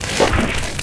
PixelPerfectionCE/assets/minecraft/sounds/mob/magmacube/jump4.ogg at mc116